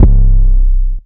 808 [highest in the room].wav